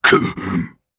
Bernheim-Vox_Damage_jp_01.wav